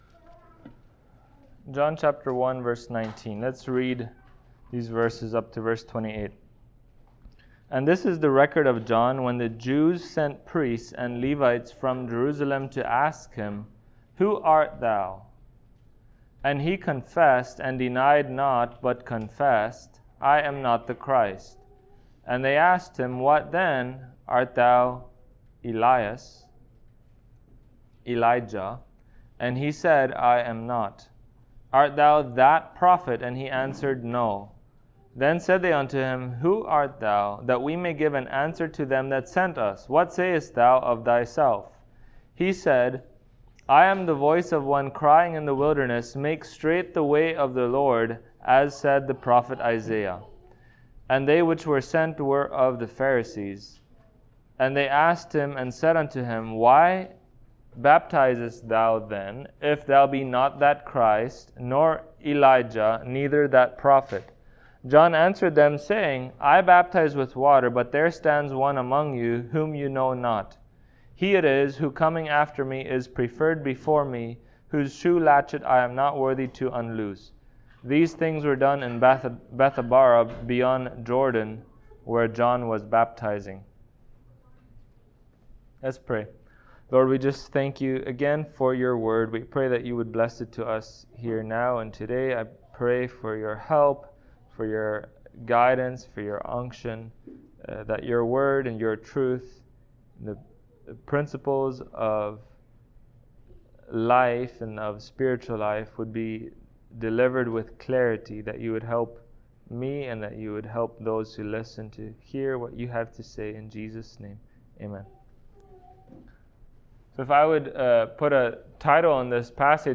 John Passage: John 1:19-28 Service Type: Sunday Morning Topics